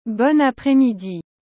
• Après: [apʁɛ] — esse “r” aí vem da garganta, bem estilo francês.
Juntando tudo: [bɔn apʁɛ midi].